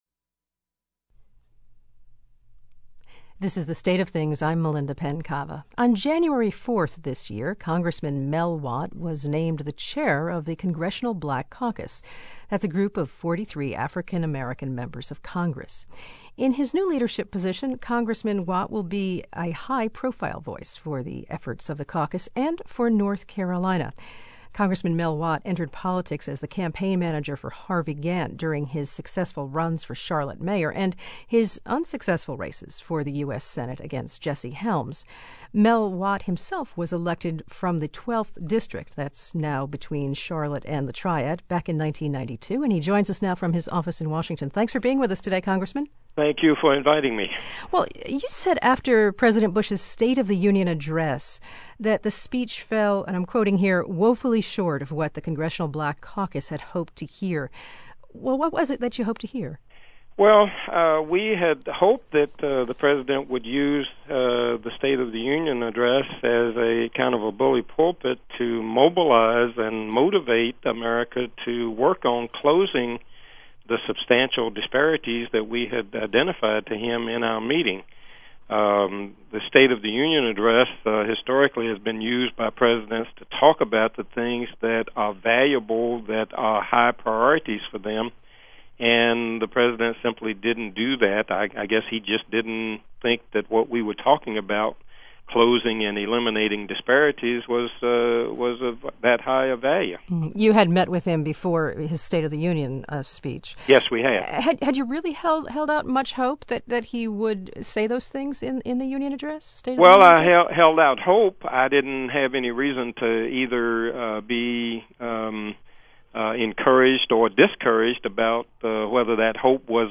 Tyson Interview – Feb 15
WUNC did a new interview with Blood Done Sign My Name author, Tim Tyson, yesterday.